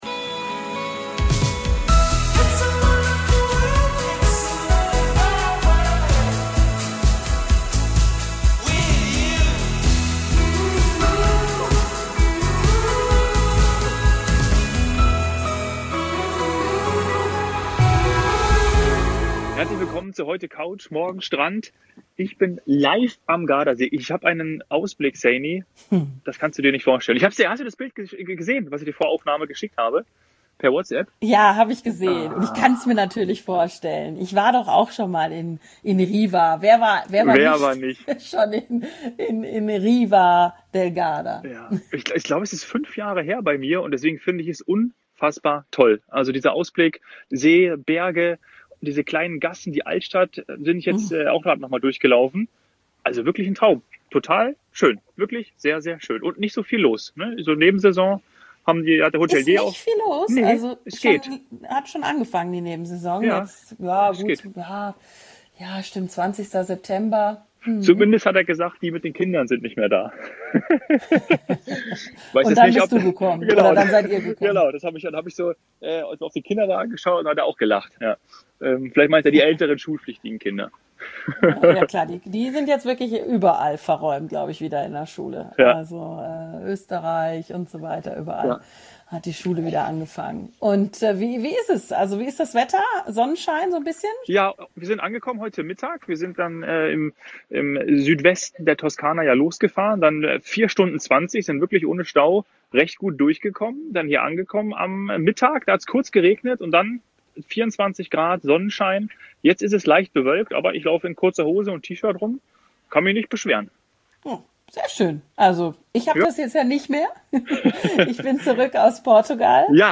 #154 Gardasee live & Alentejo